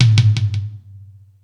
Space Drums(24).wav